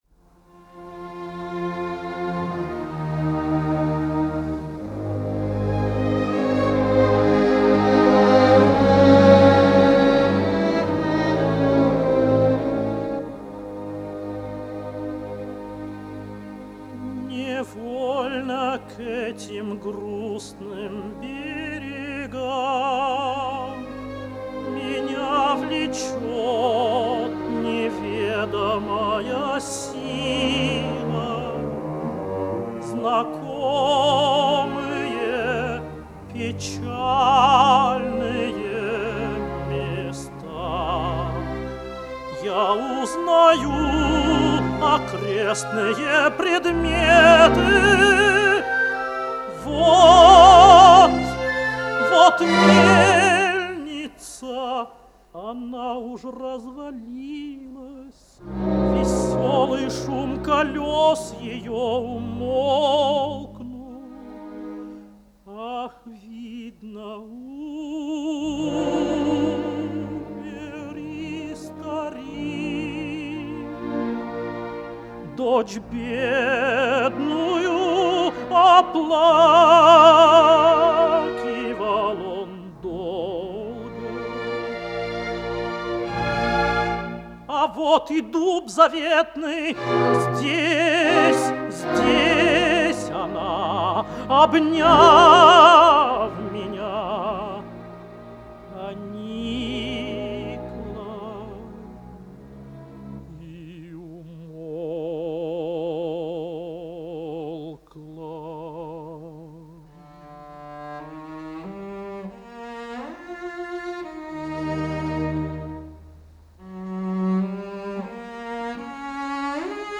10 - Михаил Александрович - А.Даргомыжский. Ария Князя из оп. Русалка (1952)